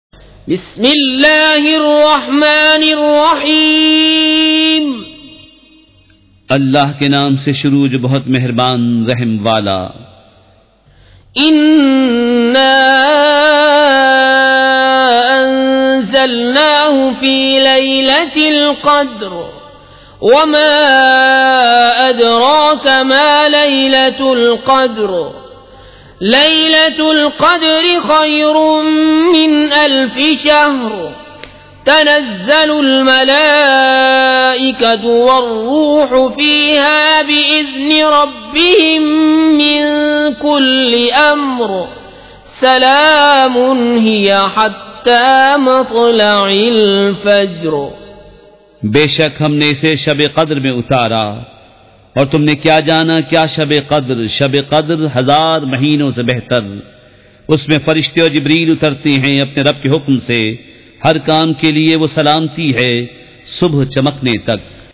سورۃ القدر مع ترجمہ کنزالایمان ZiaeTaiba Audio میڈیا کی معلومات نام سورۃ القدر مع ترجمہ کنزالایمان موضوع تلاوت آواز دیگر زبان عربی کل نتائج 2418 قسم آڈیو ڈاؤن لوڈ MP 3 ڈاؤن لوڈ MP 4 متعلقہ تجویزوآراء